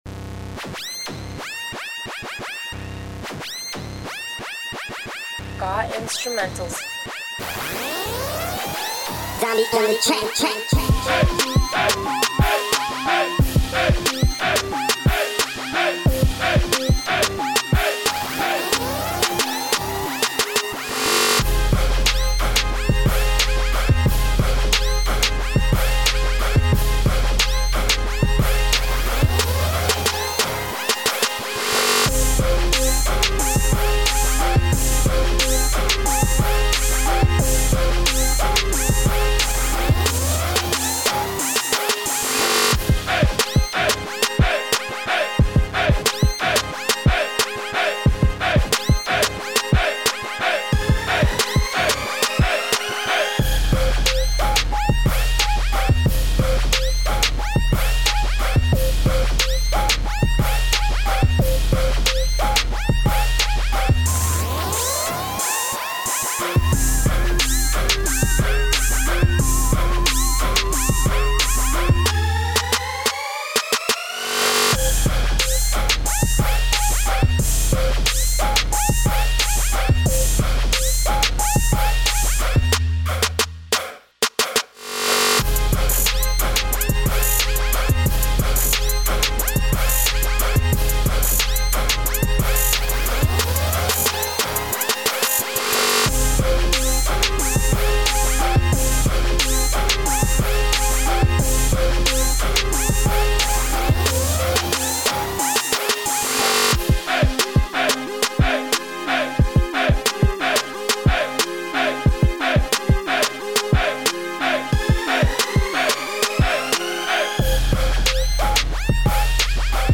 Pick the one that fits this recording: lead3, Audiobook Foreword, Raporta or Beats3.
Beats3